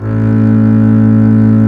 Index of /90_sSampleCDs/Roland - String Master Series/STR_Cb Bowed/STR_Cb2 f vb